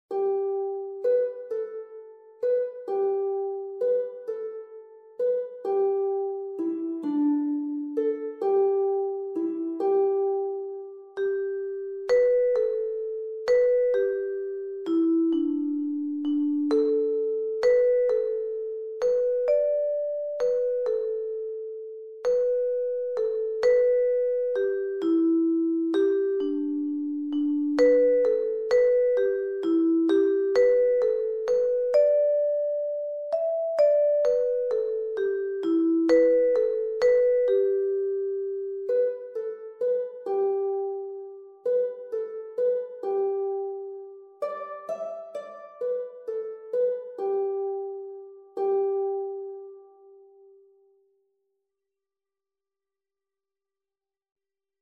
Met intro en naspel
dit liedje is pentatonisch